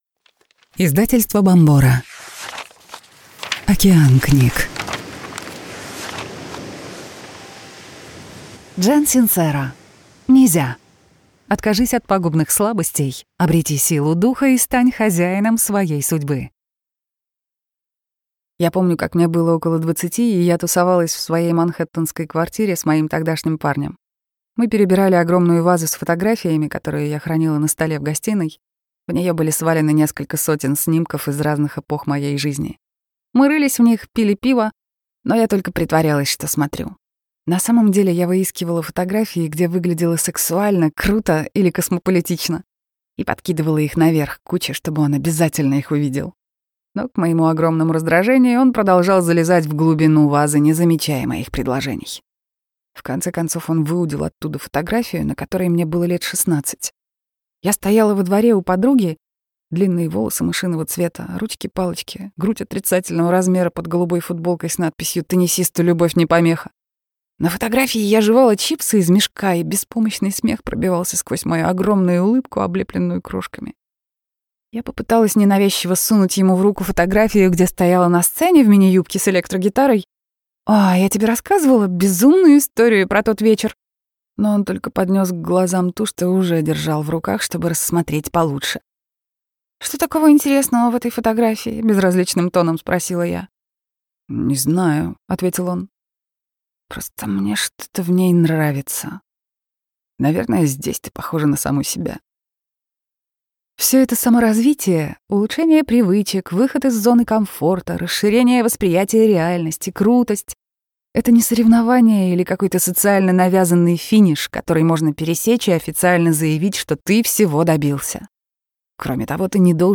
Аудиокнига НИ ЗЯ. Откажись от пагубных слабостей, обрети силу духа и стань хозяином своей судьбы | Библиотека аудиокниг